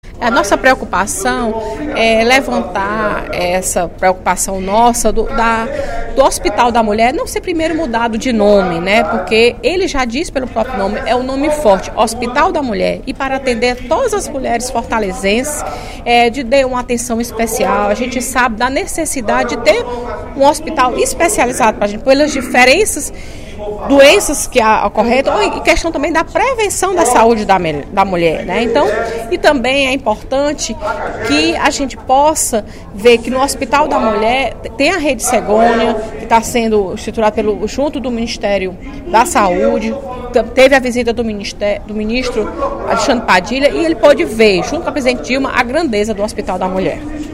A deputada Fernanda Pessoa (PR) manifestou, durante o primeiro expediente desta quarta-feira (27/02), preocupação com a possível mudança no nome do Hospital da Mulher, conforme notícias divulgadas na imprensa.